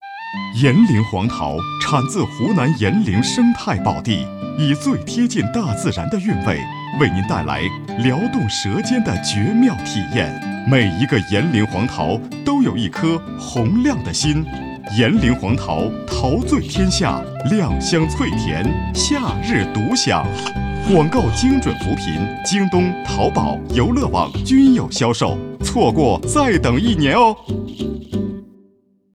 炎陵黄桃广告在中央人民广播电台播出-炎陵县人民政府门户网